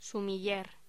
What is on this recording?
Locución: Sumiller